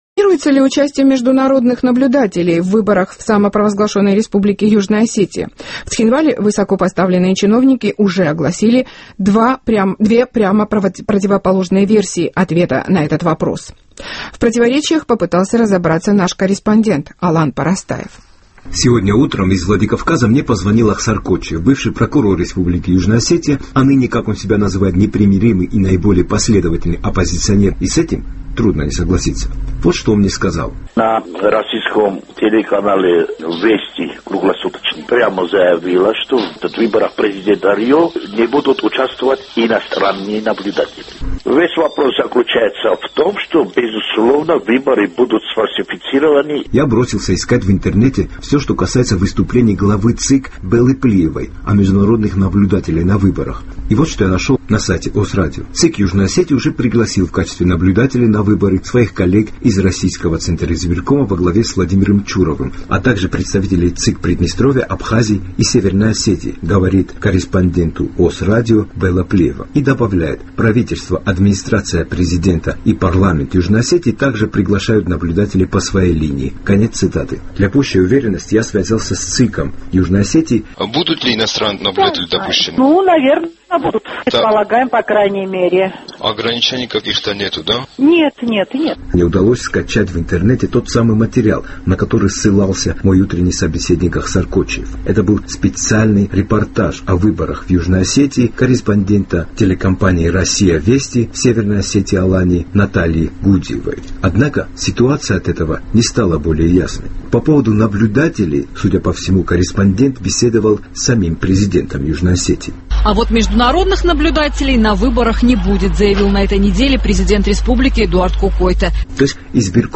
Сегодня из Владикавказа мне позвонил Ахсар Кочиев, бывший прокурор Республики Южная Осетия, а ныне, как он себя называет, непримиримый и наиболее последовательный оппозиционер нынешнему режиму Кокойты.